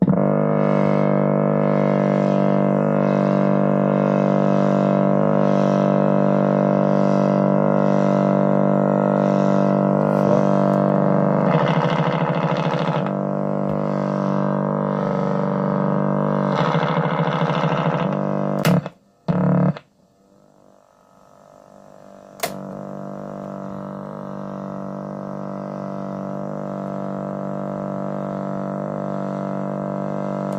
They all come from various audio cassettes.